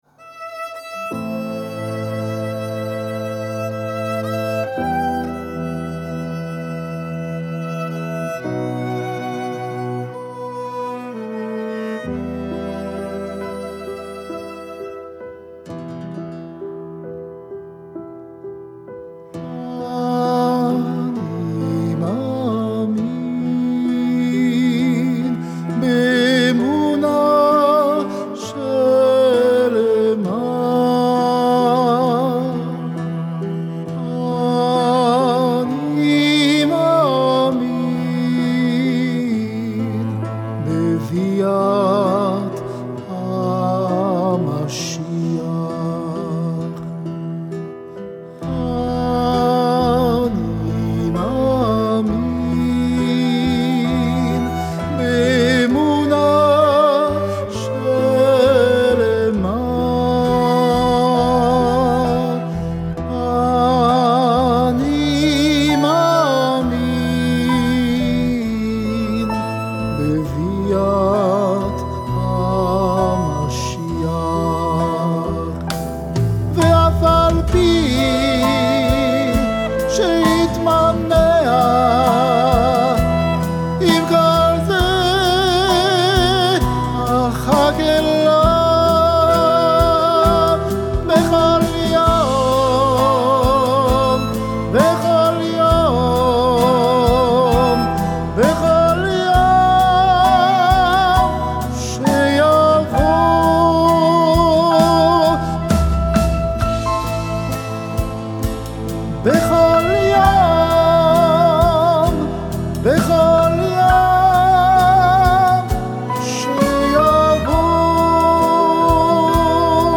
בעל קול טנור לירי